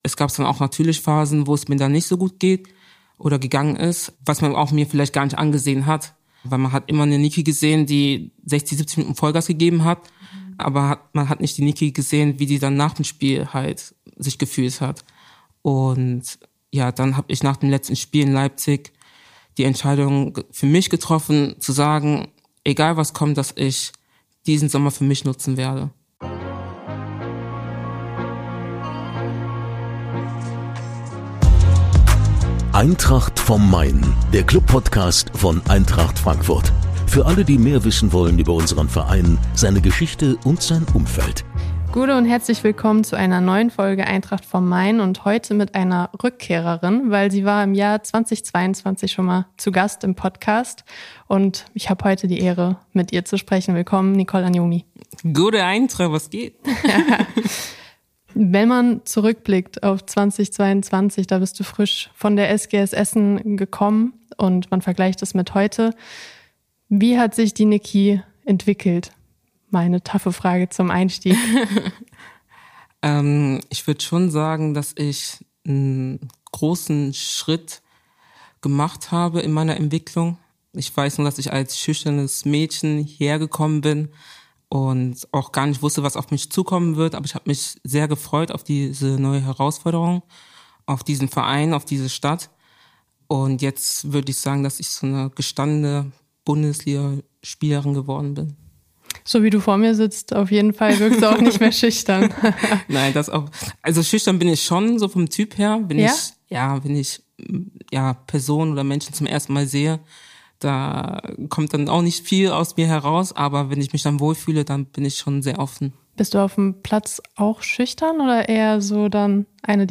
Vier Jahre, steigende Torquoten, internationale Ambitionen – und eine mutige Entscheidung für die Gesundheit: Nicole Anyomi spricht über ihre Entwicklung, den schweren EM-Verzicht, ihre Streetwear-Marke „Eternam“ und warum sie jetzt erst recht angreifen will. Ein Gespräch voller Ehrlichkeit, Zielstrebigkeit und Vorfreude auf die neue Saison.